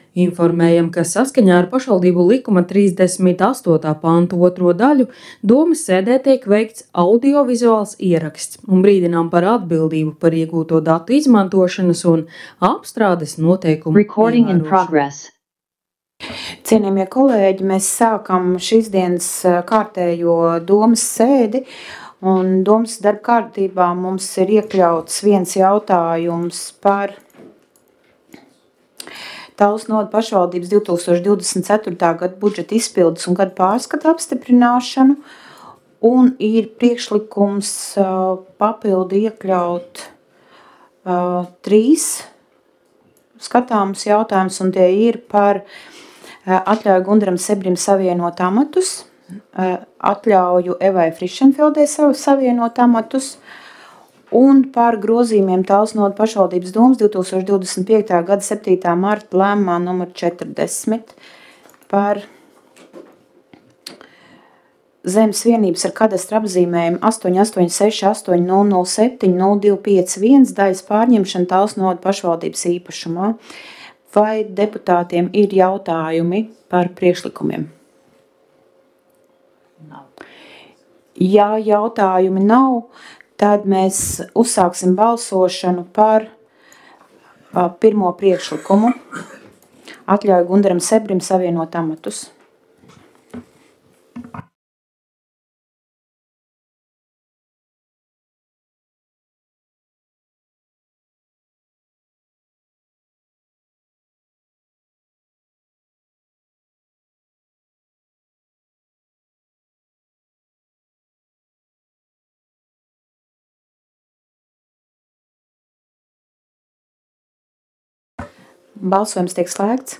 Talsu novada domes sēde Nr. 7
Domes sēdes audio